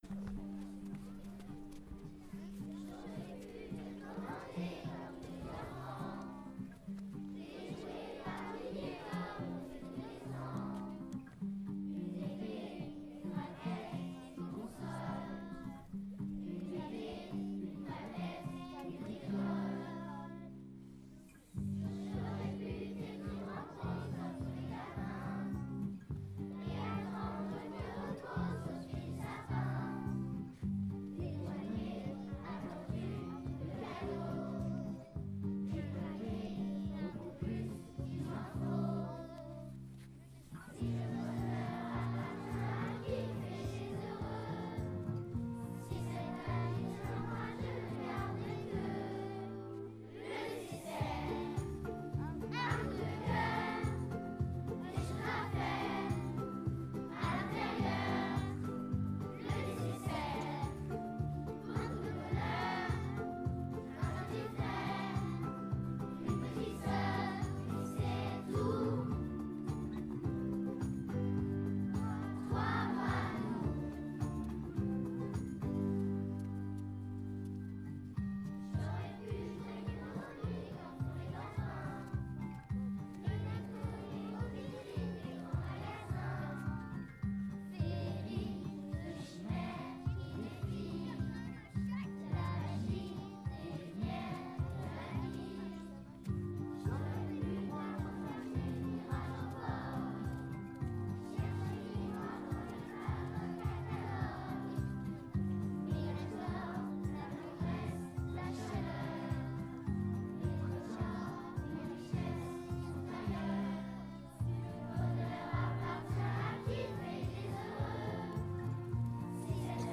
Les élèves ont pu déguster un goûter et un chocolat chaud après avoir chanté devant les parents d’élèves.